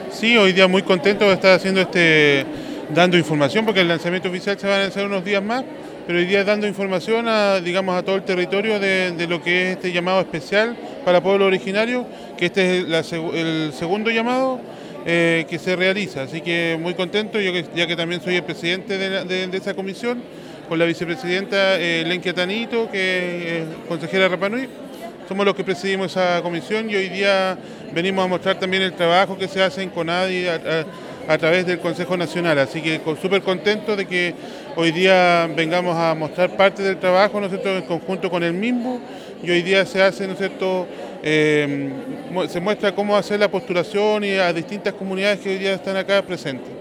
Por último, el Consejero Nacional Indígena ante Conadi y Presidente de la Comisión de Vivienda del organismo, Mario Inay, sostuvo que es muy importante entregar la información a la comunidad y dar a conocer el trabajo que se está realizando.